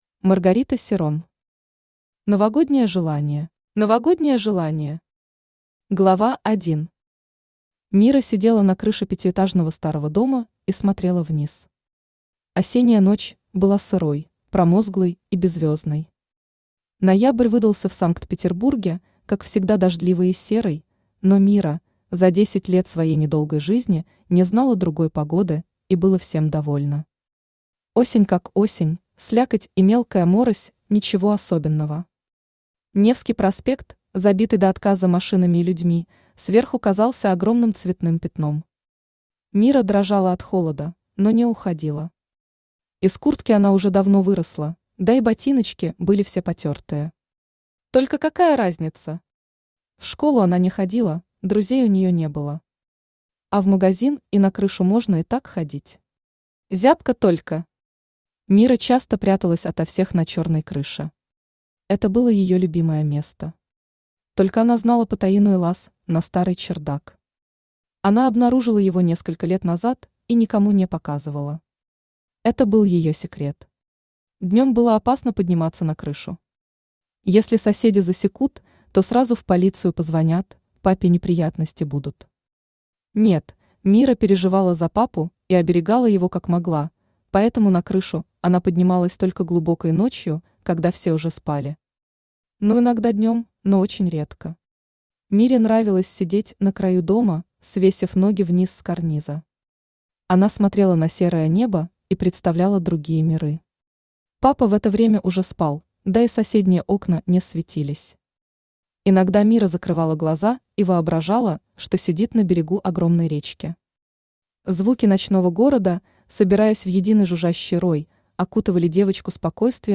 Aудиокнига Новогоднее желание Автор Маргарита Серрон Читает аудиокнигу Авточтец ЛитРес.